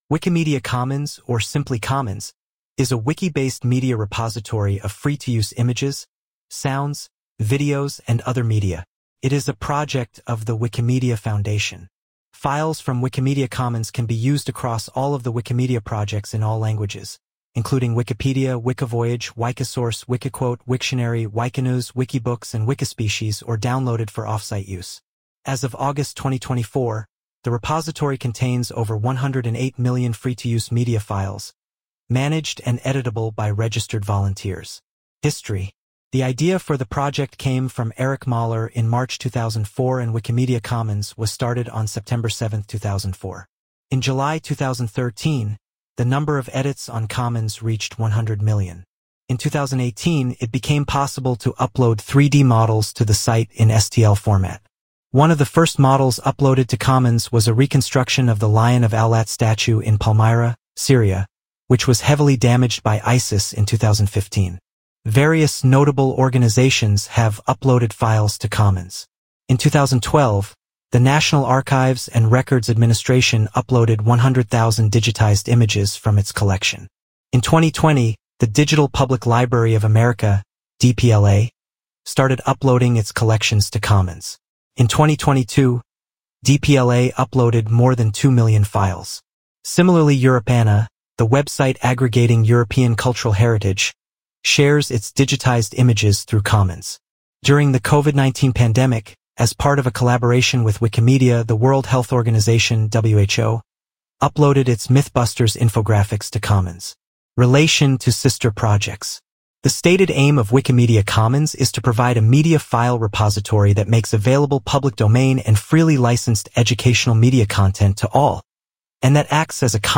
Spoken text audio
Wikipedia_-_Wikimedia_Commons_(spoken_by_AI_voice).mp3